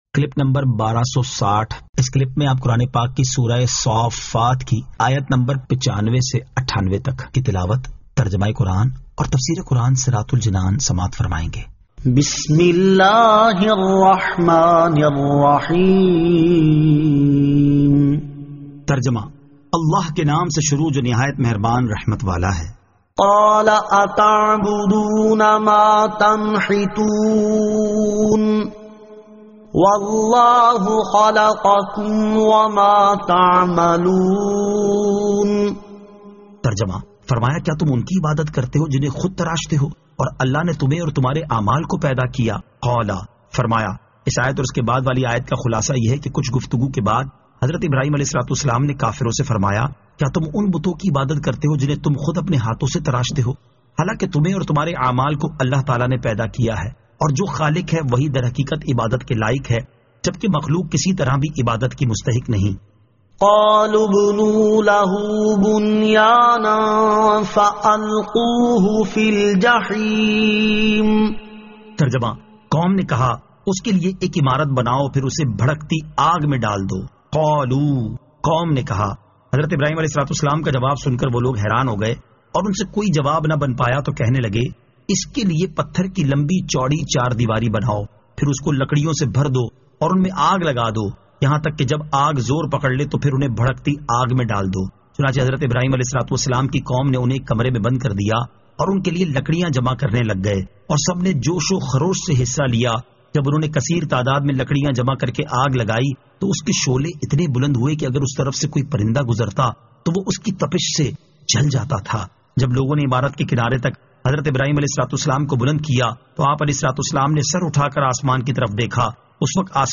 Surah As-Saaffat 95 To 98 Tilawat , Tarjama , Tafseer